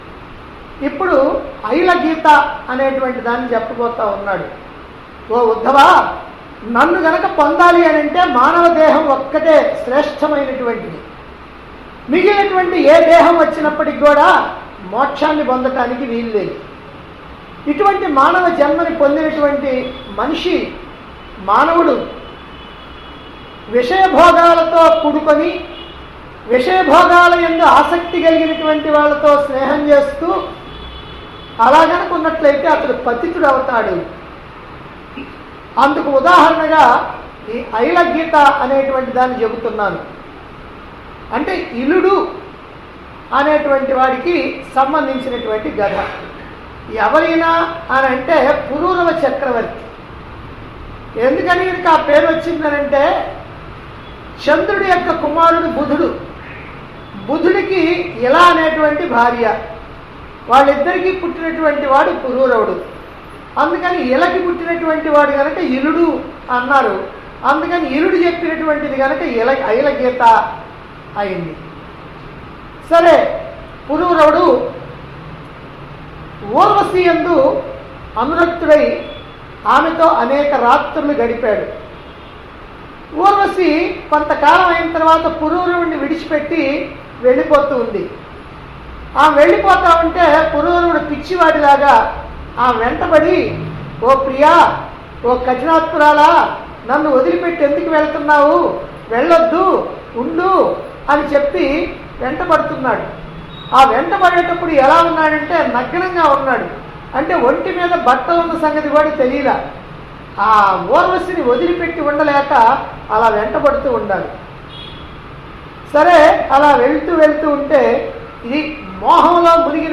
Other Gita Grandhas - Aila Gita Discourses Recorded On 11-Mar-2019 To 05-Apr-2019 Discourse Conducted At Chilakaluripet, Guntur Dt. Andhra Pradesh.